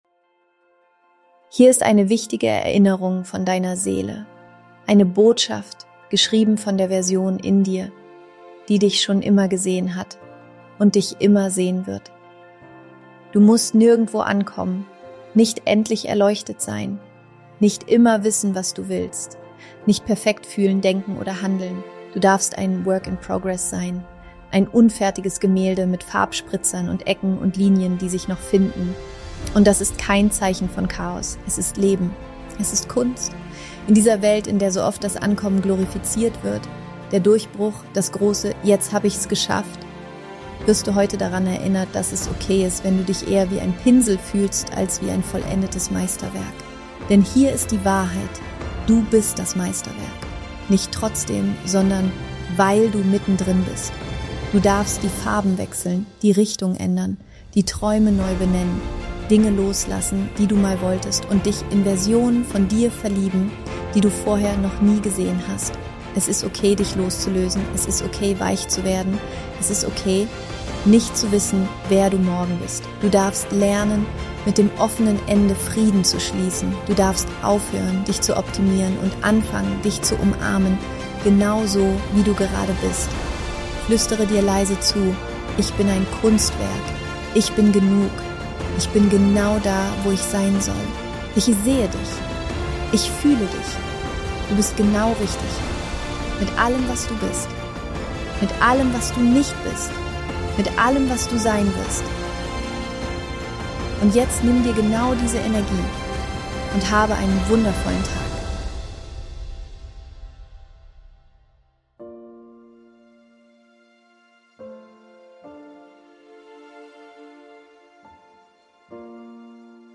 Viel Spaß mit diesem Mini-Powertalk!